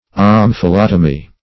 Search Result for " omphalotomy" : The Collaborative International Dictionary of English v.0.48: Omphalotomy \Om`pha*lot"o*my\, n. [Gr.
omphalotomy.mp3